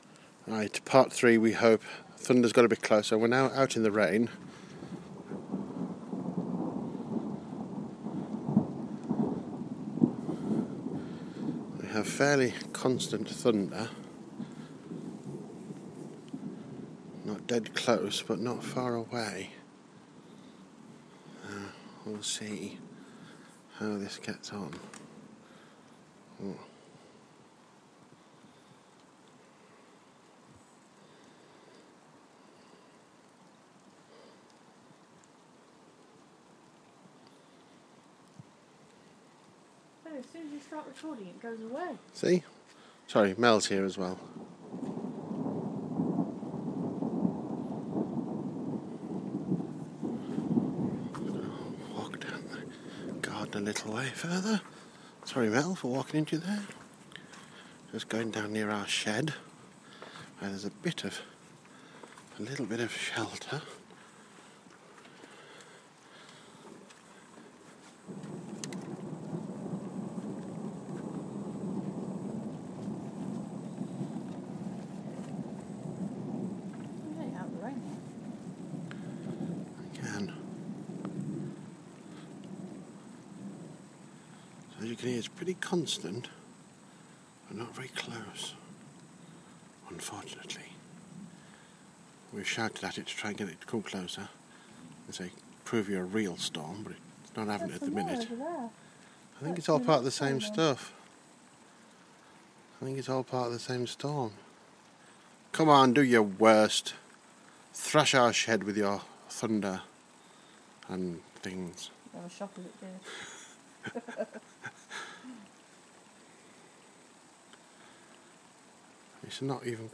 Thunder in the distance part three